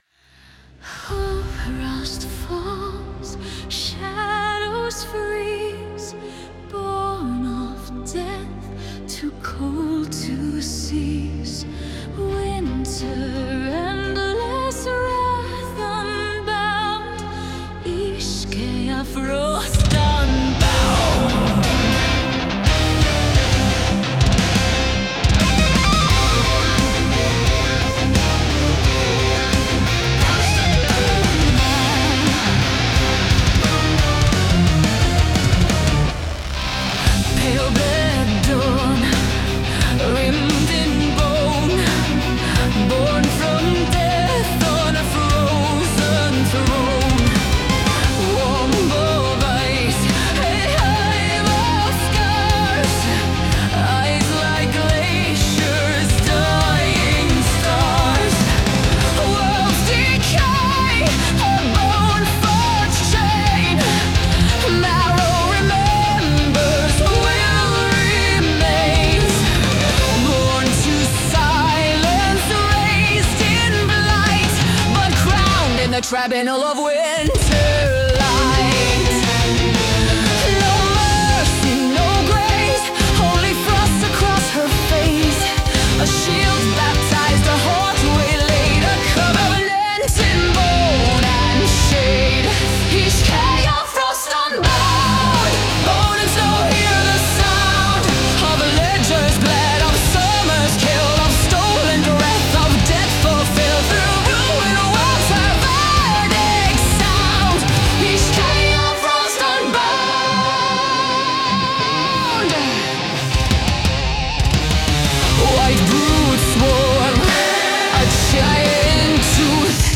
Her Theme. I am the female lead singer and song writer.